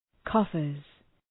Προφορά
{‘kɔ:fərz}